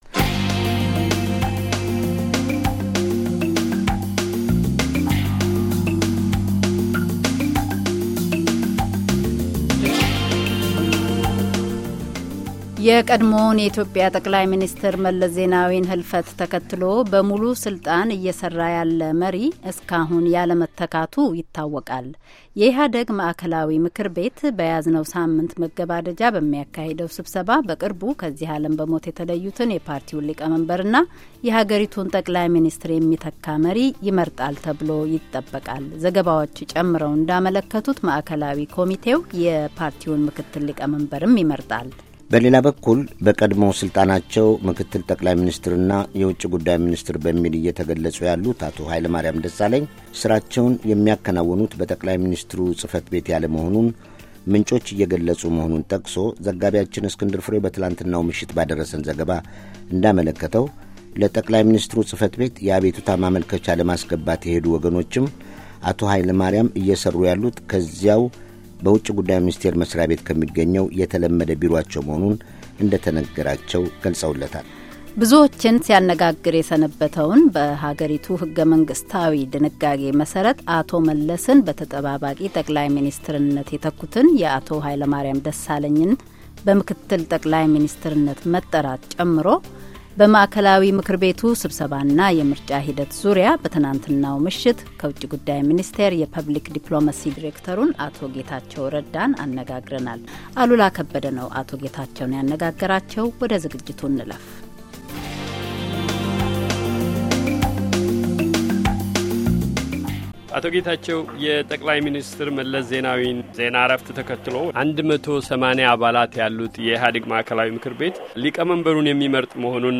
በኢህአዴግ ማዕከላዊ ምክር ቤት የመሪዎች ምርጫ ሂደት፤ በወቅቱ የአገሪቱ መሪ ማንነትና ተያያዥ ርዕሰ ጉዳዮች ዙሪያ የተካሄደ ቃለ ምልልስ።